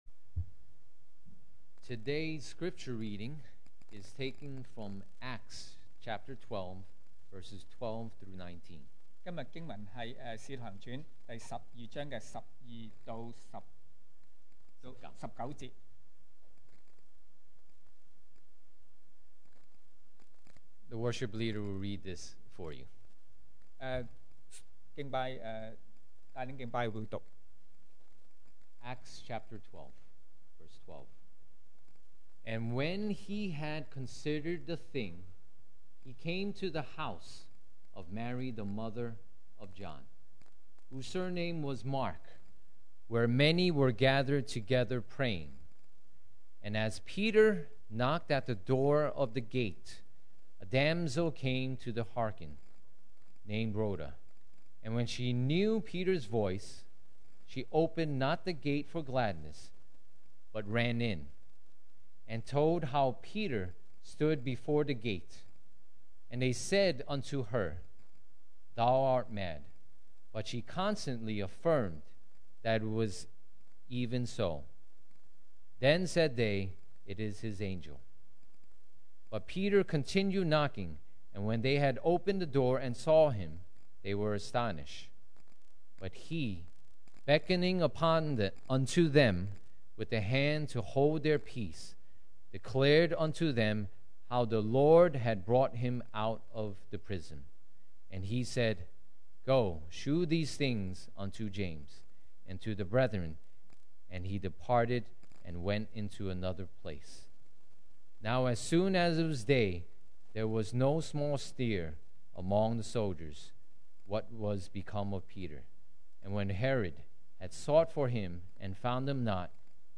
2020 sermon audios
Service Type: Sunday Morning